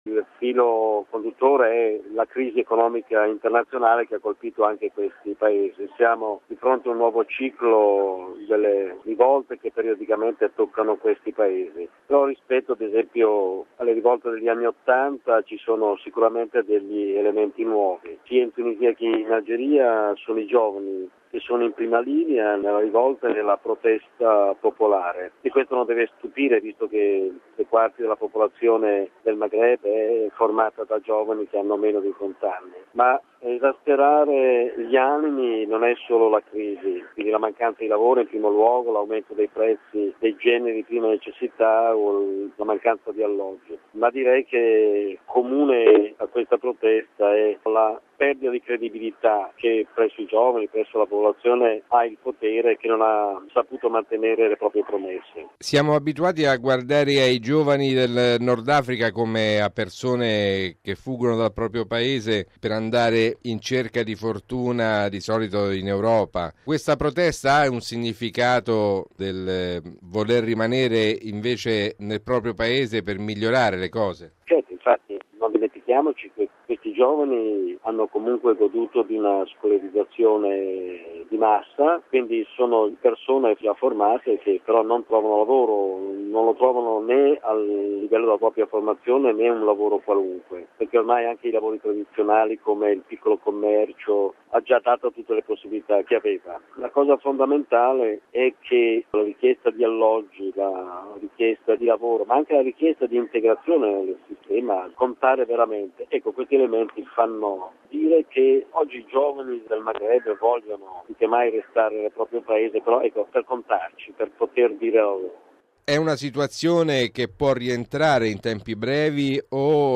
raggiunto telefonicamente a Tunisi: